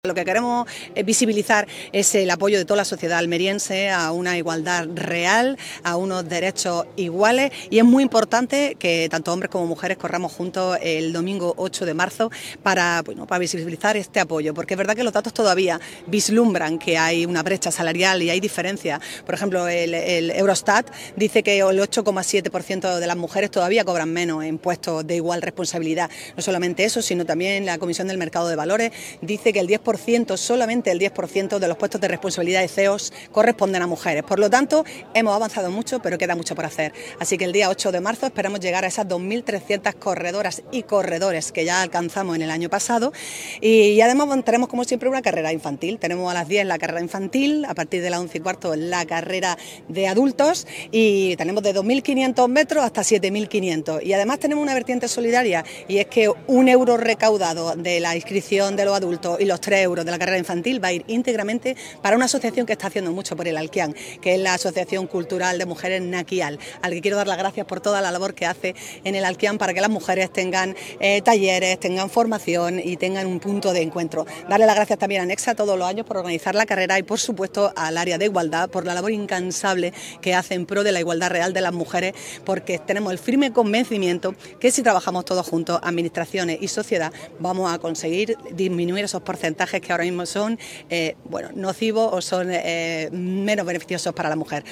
La alcaldesa, María del Mar Vázquez, presenta la camiseta y afirma que “correr juntos, hombres y mujeres, simboliza una sociedad que avanza unida, sin dejar a nadie atrás”
Estas declaraciones las ha realizado esta mañana, en la tradicional presentación de la camiseta, en la Plaza de la Constitución, rodeada de una amplia representación de la Corporación Municipal, en el inicio de la cuenta atrás para dar salida, el 8M, a las 11:15 horas, a esta popular prueba, con tres distancias, 2,5 kms., 5 kms. y 7,5 kms.
ALCALDESA-PRESENTACION-CAMISETA-CARRERA-MUJER.mp3